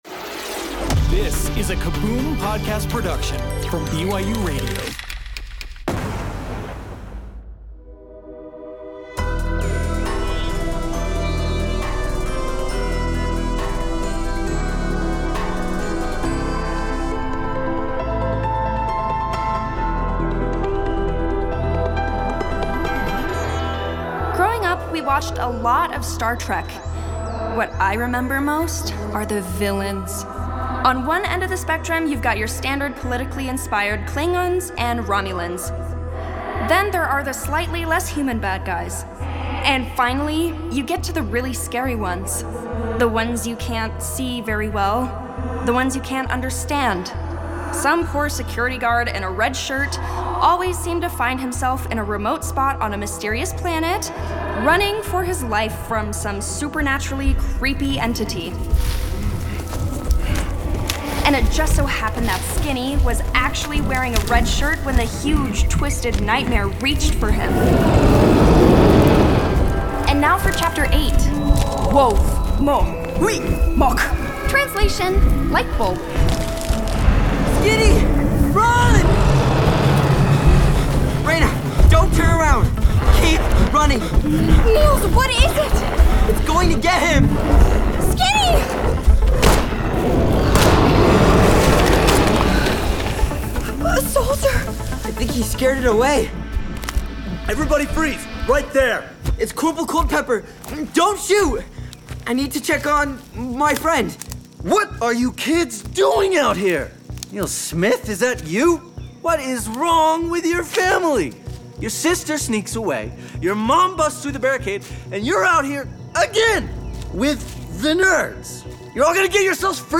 Whether it's a kid hiding a baby dragon in his attic, a high school mascot protecting the football team from an ancient Viking curse, or a little sister vanishing when a teleportation experiment goes wrong, Kaboom has an audio adventure for you! The whole family will love listening to these original fiction stories together, which feature full casts of talented voice actors and cinematic sound design.